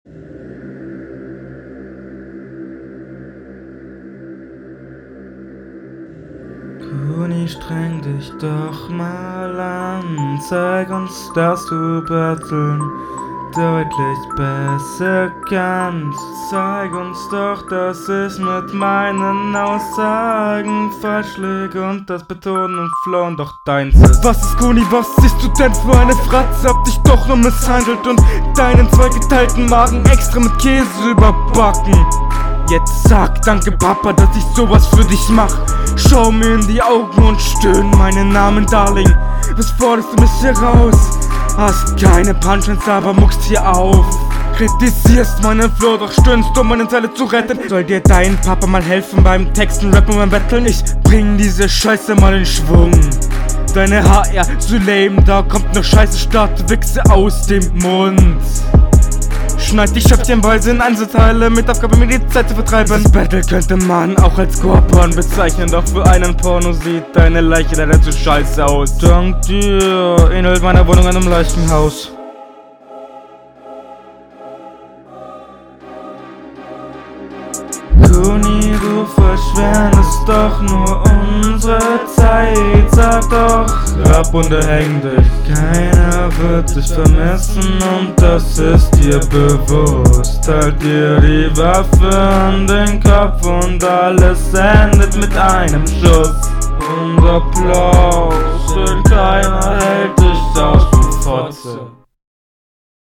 War sehr anstengend anzuhören aber wenn man auf den Text achtet versteht man das meiste.
Dieser Gesang am Anfang ist wirklich schrecklich.
Soundbild gefällt mir.